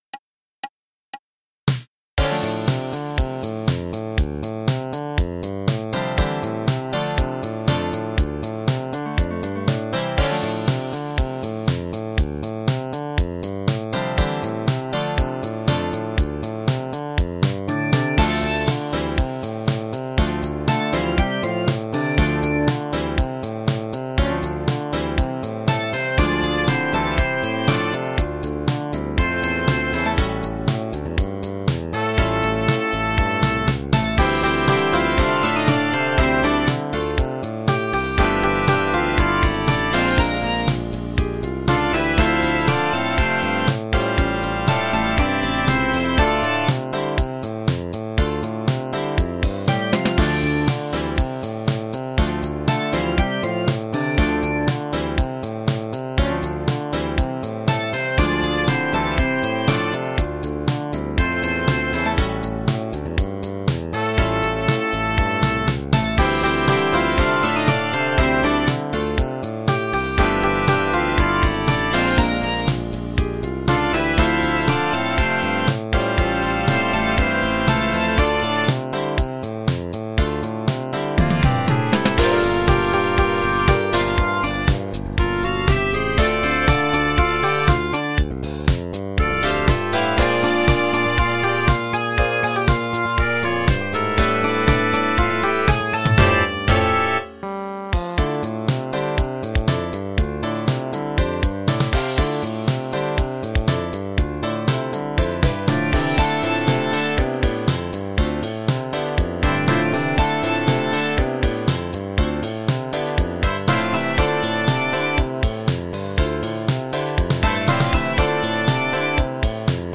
Blues in D-Moll, für große Besetzung